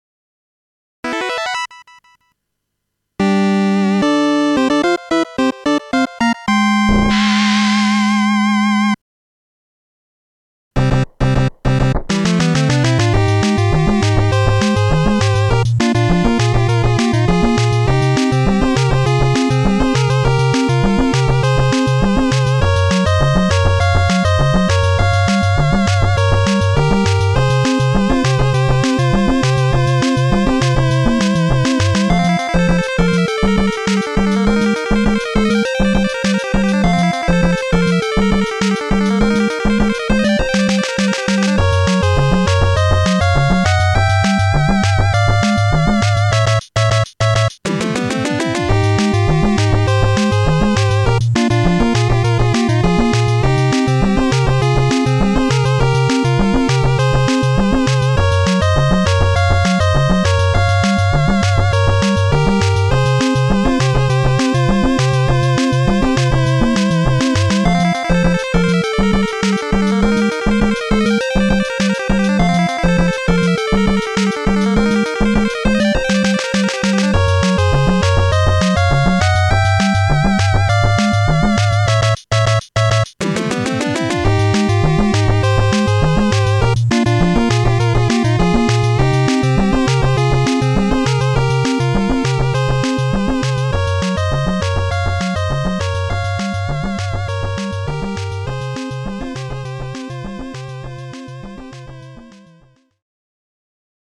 サウンドを、YAMAHAのXG音源「MU100」を用いて擬似的に再現して制作したオリジナル曲たちです。
MP3ファイルは全て「YAMAHA MU100」で制作したMIDIデータを「YAMAHA MU1000EX」で再生、
緊迫感→安堵感を短いアレンジで。